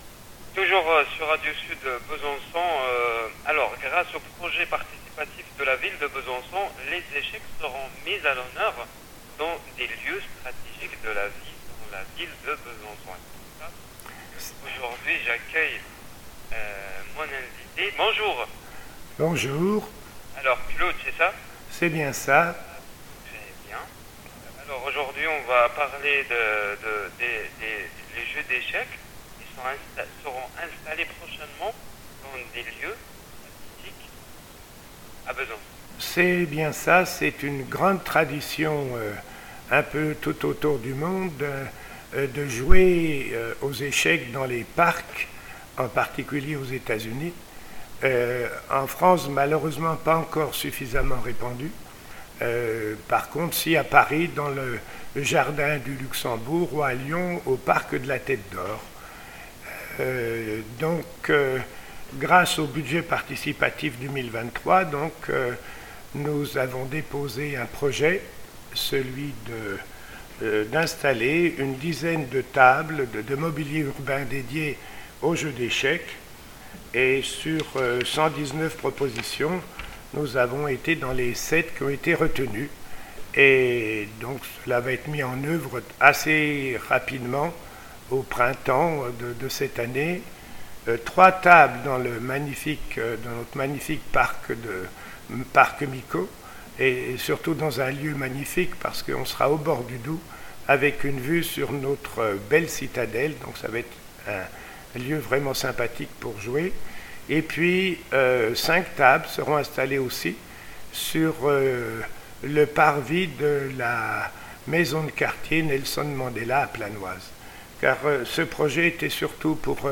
Les tables demandées font parler d’elles, mises en avant sur Facebook et Instagram. Ci-dessous, une interview récente diffusée sur Radio Sud.